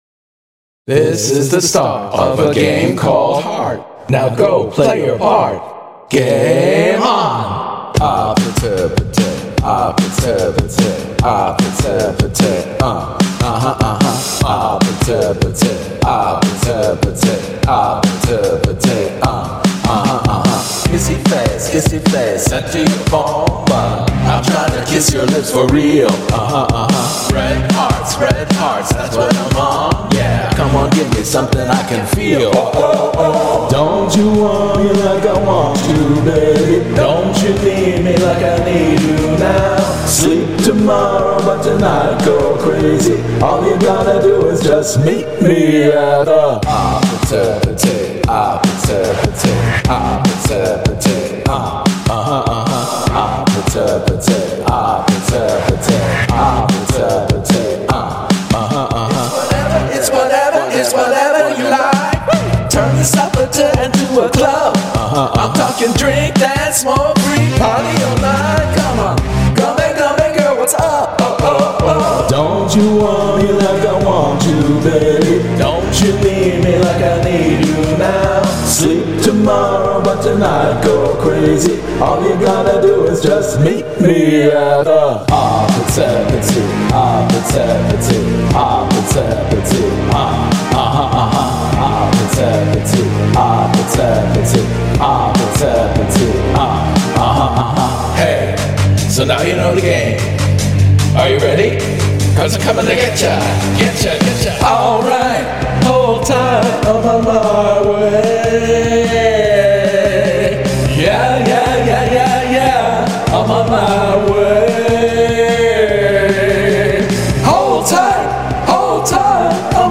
vocals
karaoke arrangement
My cover of the incredibly catchy K-pop mega-hit